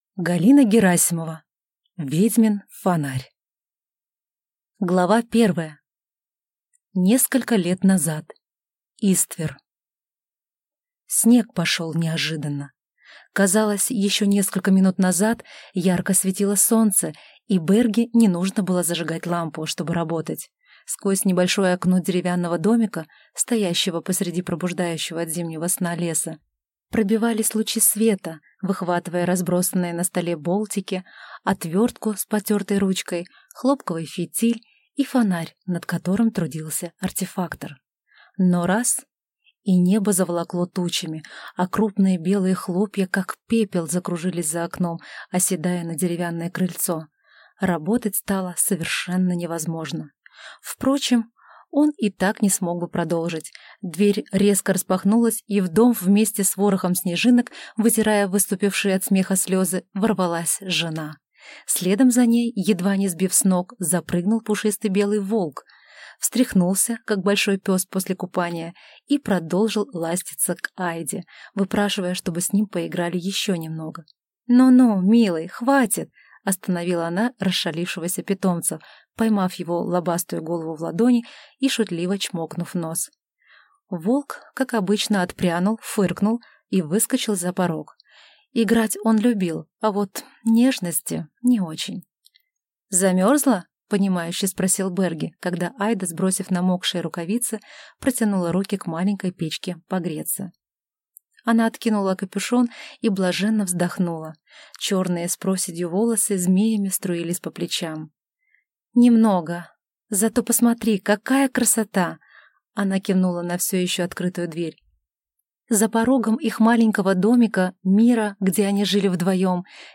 Аудиокнига Ведьмин фонарь | Библиотека аудиокниг